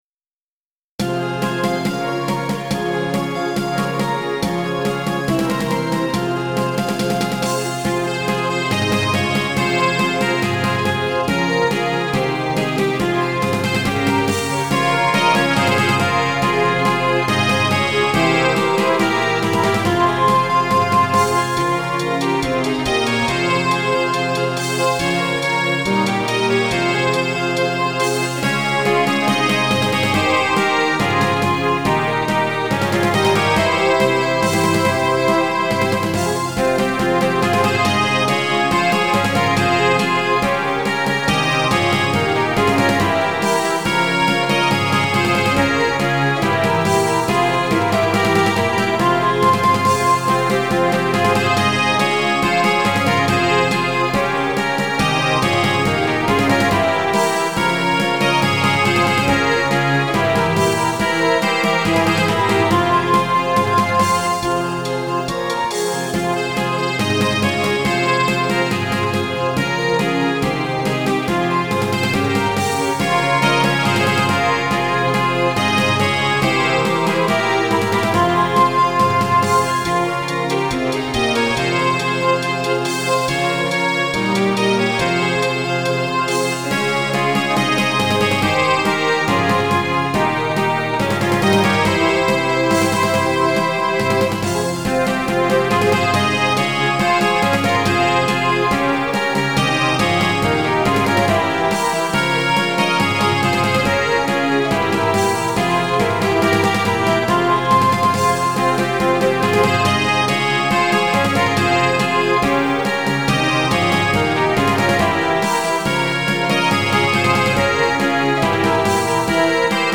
ヘ短調